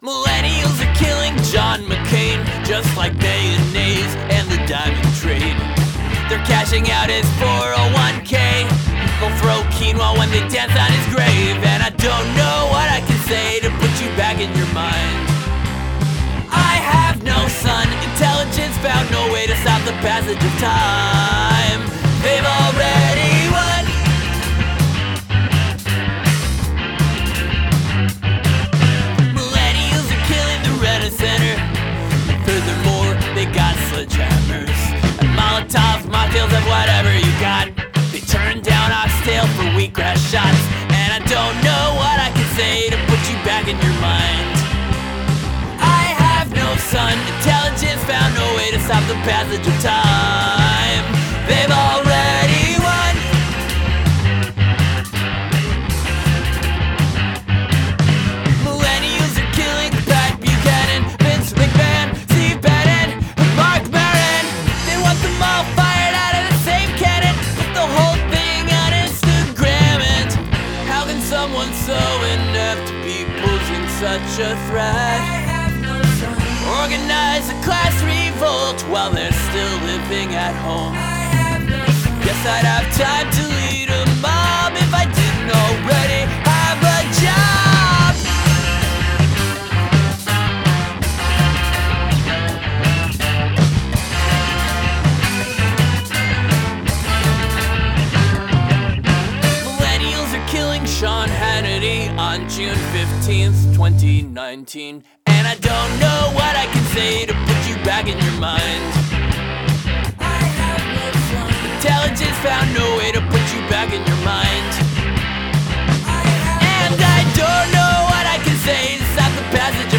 post-mcluhanist chamber punk for the cultural epidemic